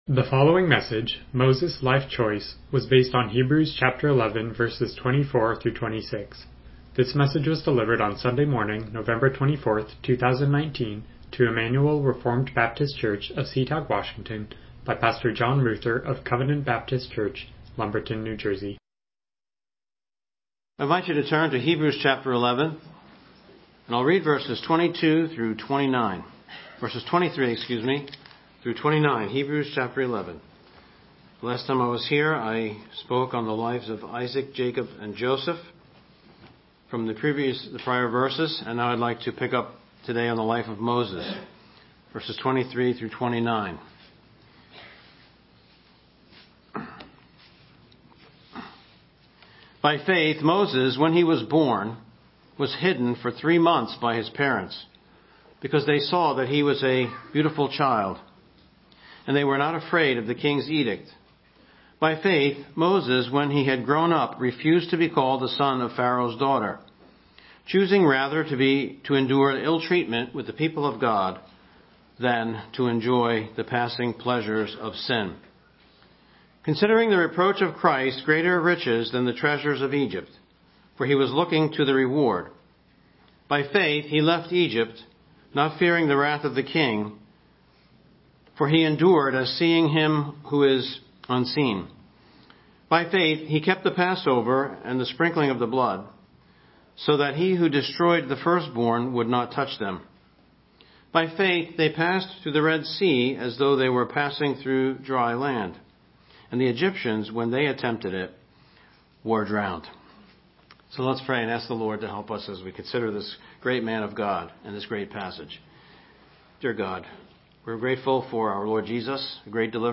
Hebrews 11:24-26 Service Type: Morning Worship « The Holy Spirit